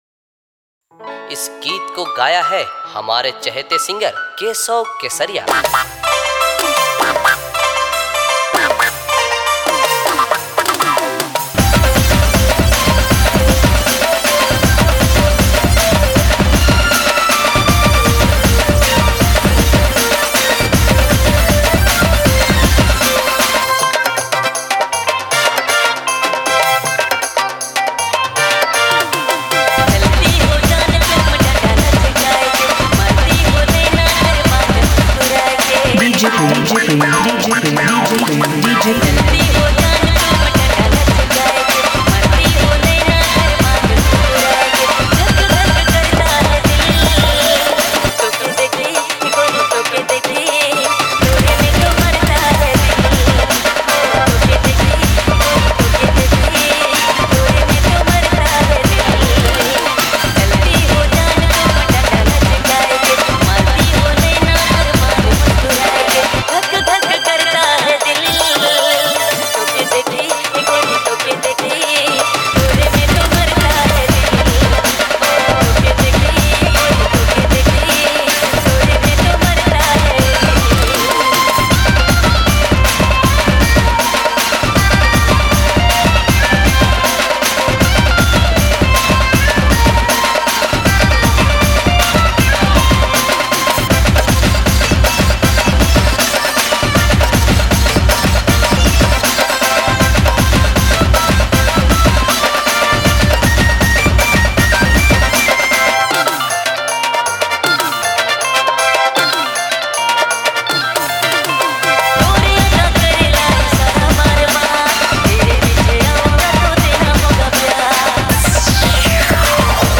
enjoy the perfect blend of traditional and modern beats